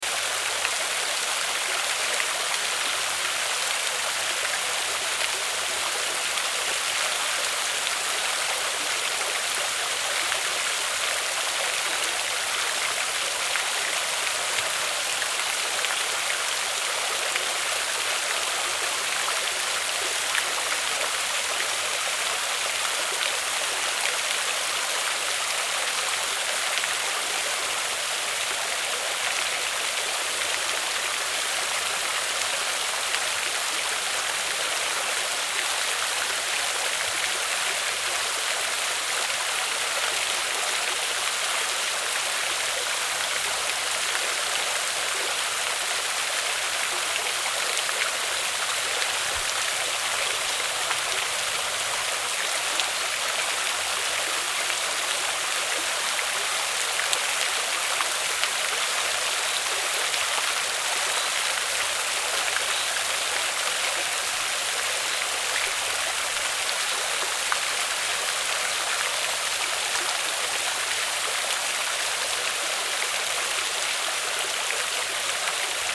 Fountain Sound Effect
Flowing stream from a small water fountain. Relaxing sound of water gently flowing from a small fountain in a natural setting.
Water sounds. Audio loop.
Fountain-sound-effect.mp3